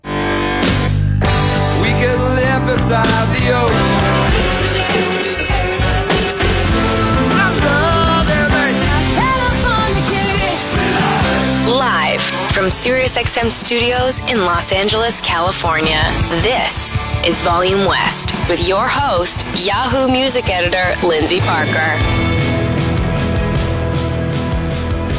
(captured from the online stream)
04. introduction-promo (0:25)